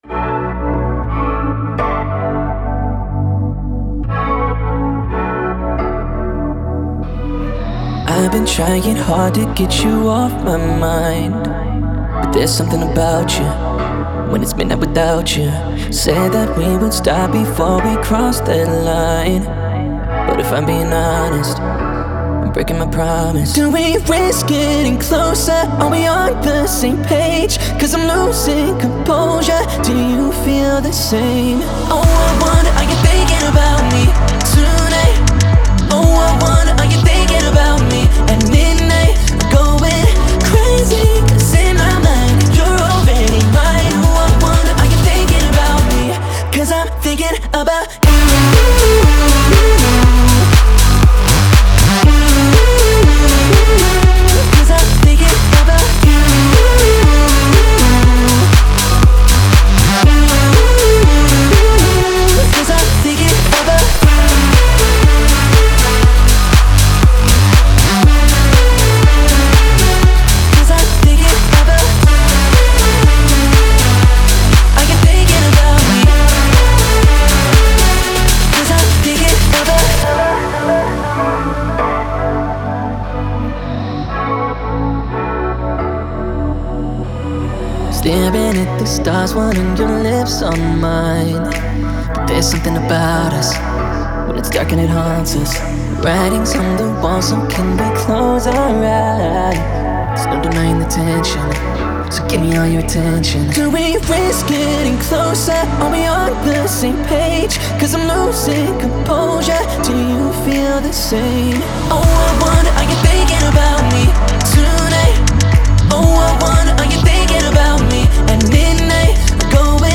это захватывающая электронная трек в жанре EDM